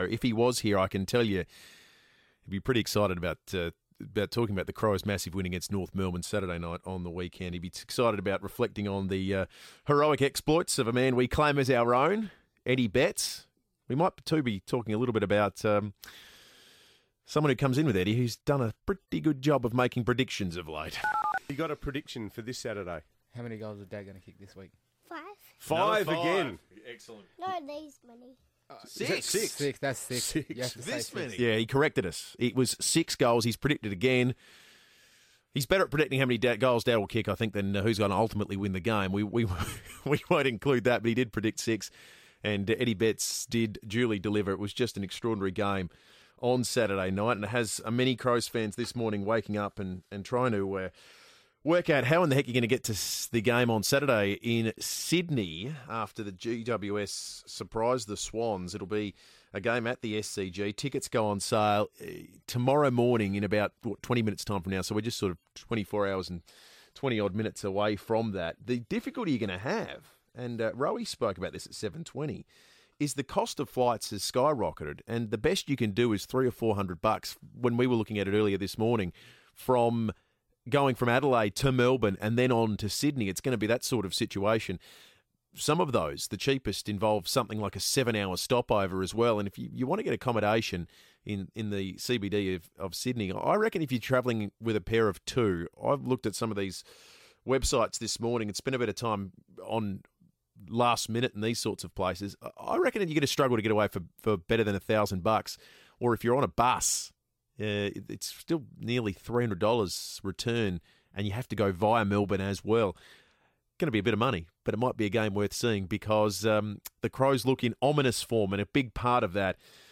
Adelaide defender Brodie Smith talks to FIVEaa following the Crows' elimination final victory over North Melbourne